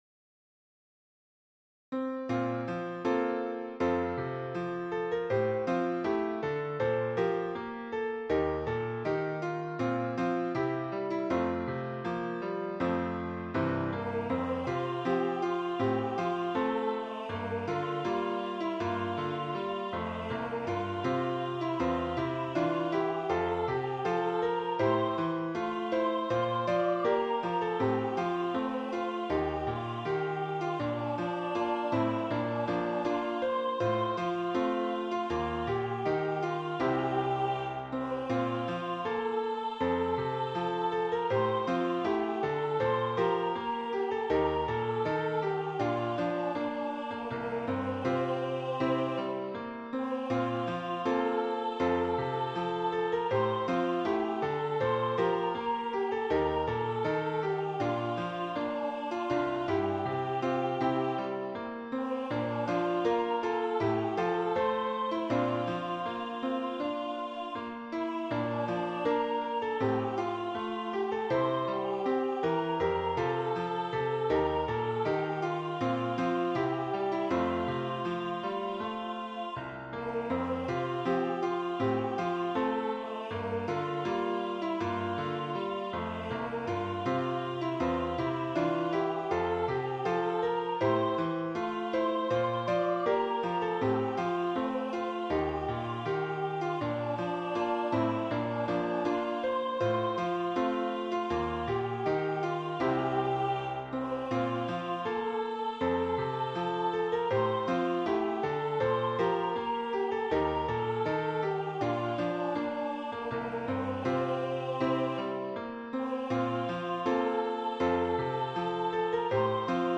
I love an anthem, and i love to write about testimony.